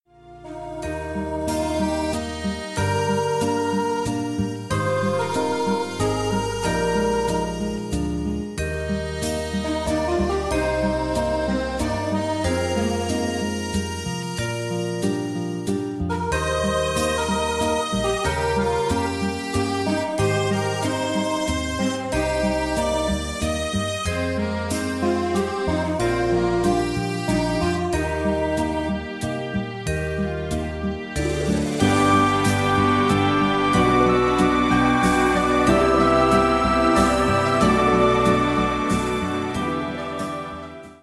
Category: Rock Tag: 70s Rock